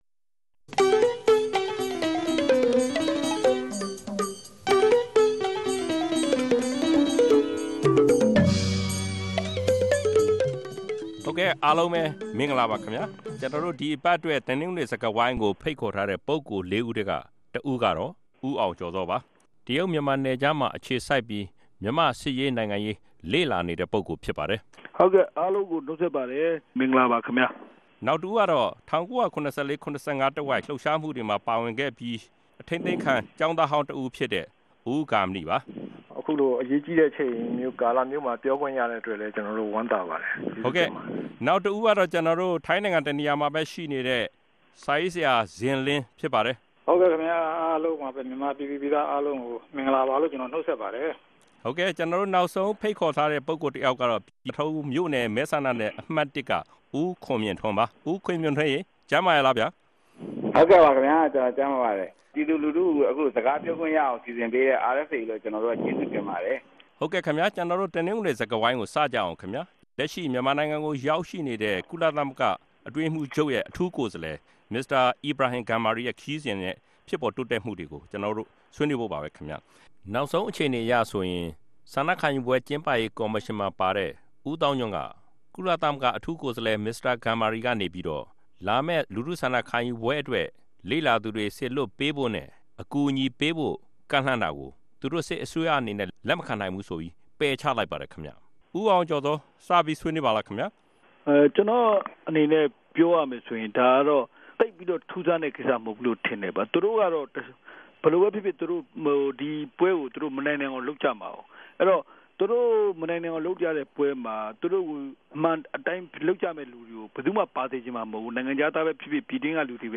တပတ်အတြင်း သတင်းသုံးသပ်ခဵက် စကားဝိုင်း